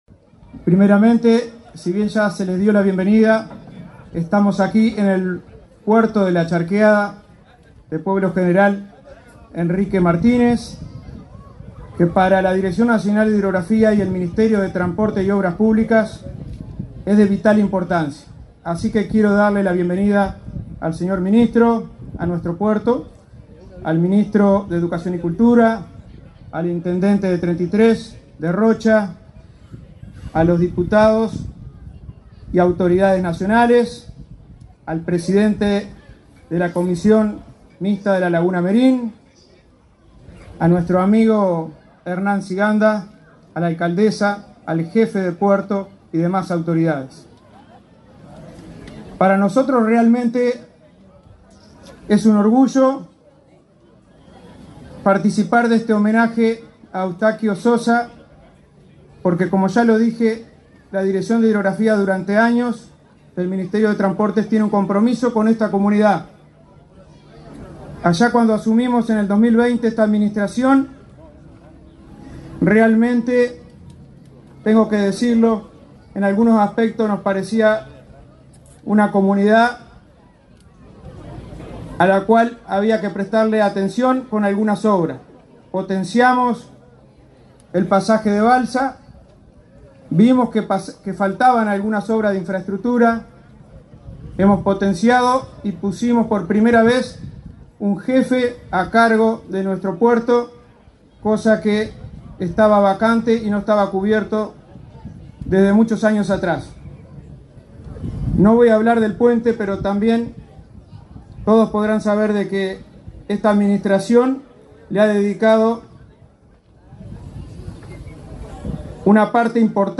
El director nacional de Hidrografía, Marcos Paolini, y el ministro de Transporte, José Luis Falero, se expresaron durante el acto de inauguración de
Palabras de autoridades del MTOP en Treinta y Tres 01/11/2024 Compartir Facebook X Copiar enlace WhatsApp LinkedIn El director nacional de Hidrografía, Marcos Paolini, y el ministro de Transporte, José Luis Falero, se expresaron durante el acto de inauguración de luminarias en la cabecera del puente La Charqueada, en el departamento de Treinta y Tres.